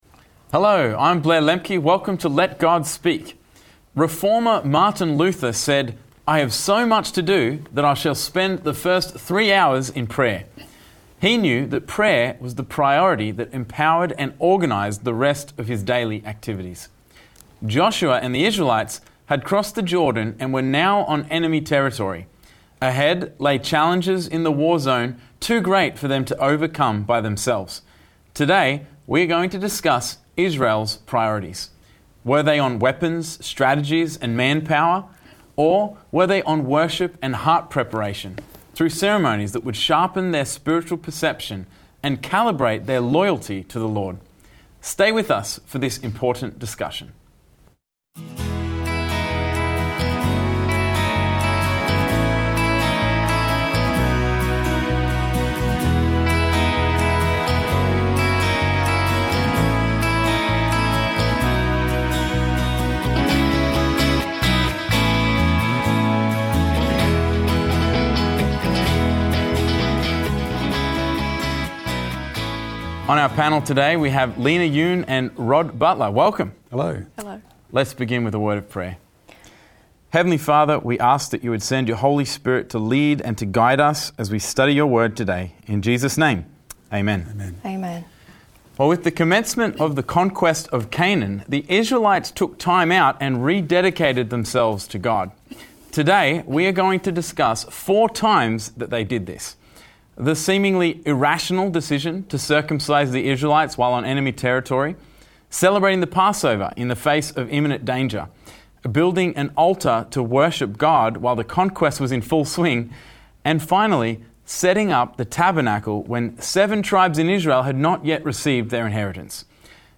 Stay with us for this important discussion.